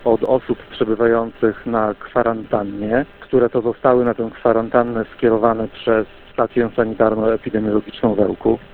Jak mówi Rafał Wilczewski, wójt gminy Prostki, samorząd nie ma szczegółowych danych.